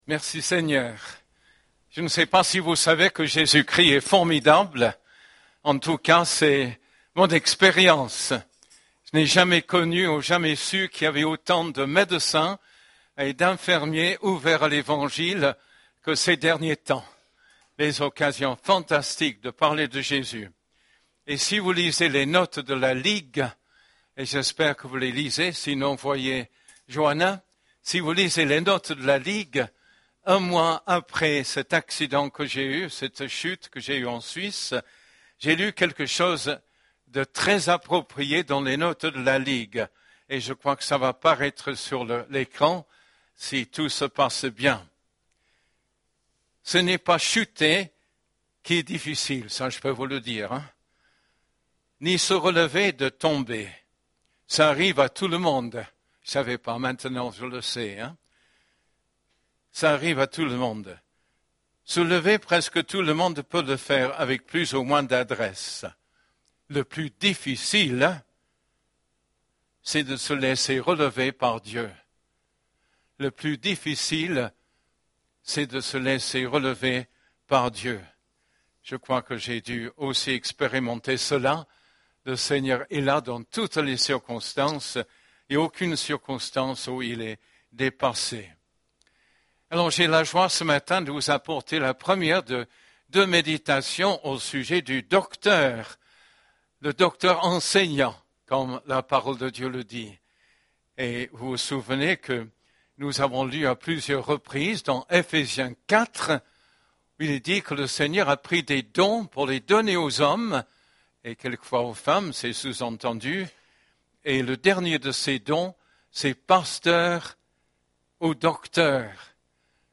Culte du 25 novembre